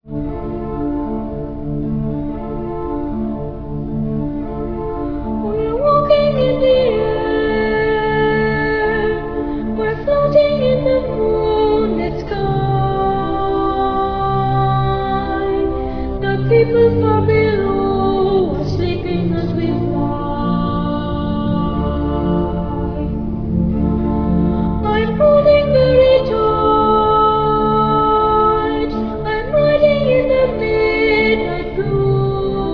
boy soprano
organ.